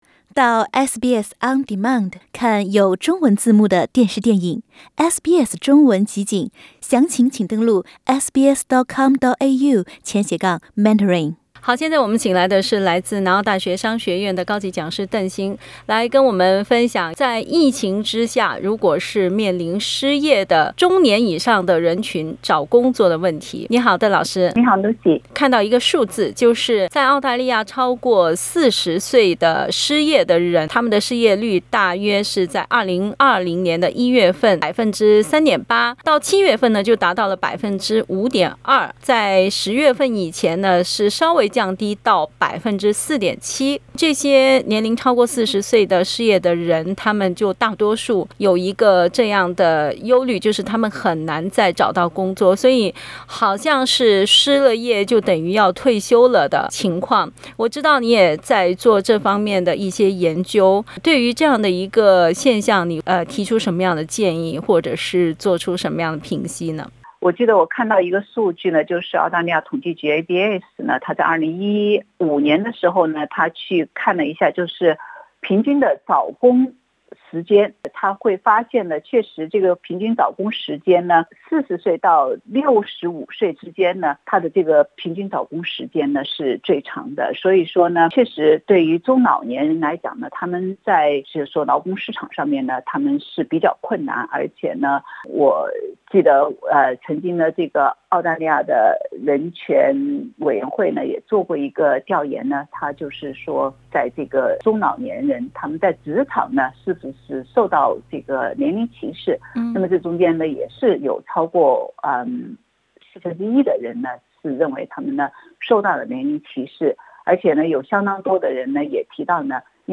（请听采访） 澳大利亚人必须与他人保持至少 1.5 米的社交距离，请查看您所在州或领地的最新社交限制措施。